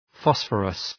Προφορά
{‘fɒsfərəs}